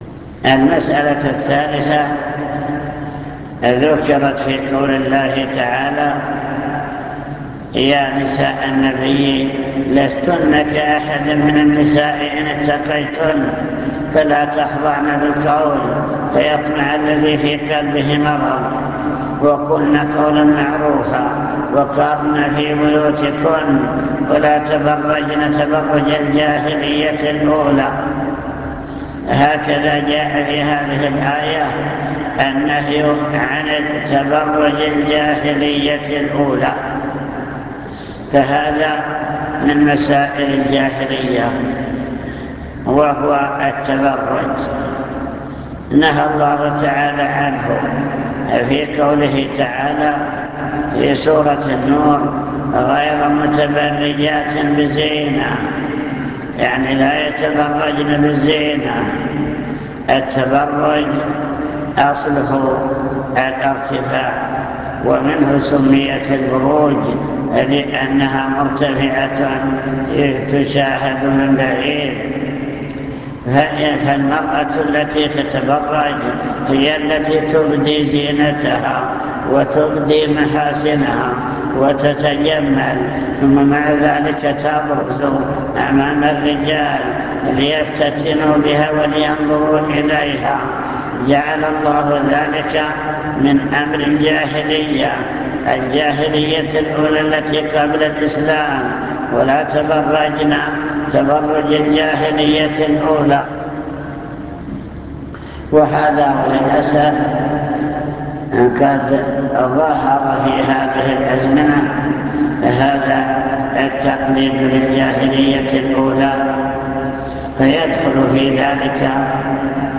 المكتبة الصوتية  تسجيلات - محاضرات ودروس  مسائل الجاهلية